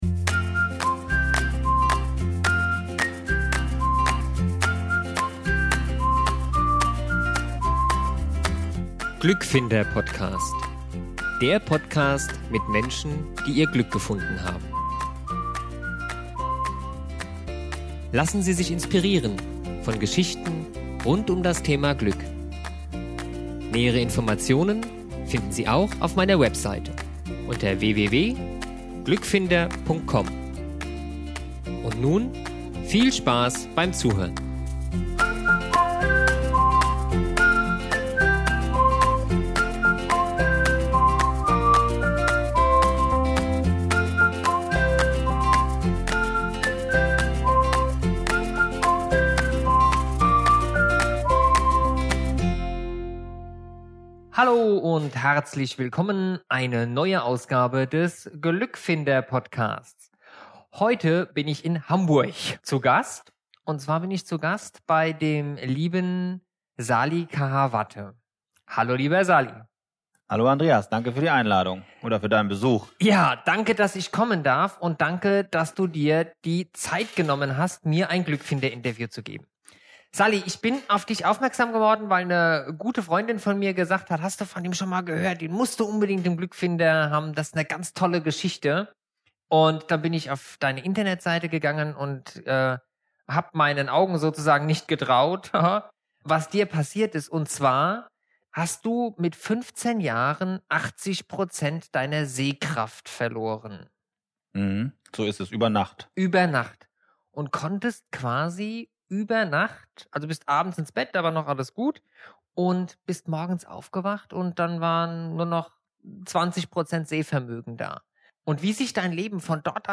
im Glückfinder Interview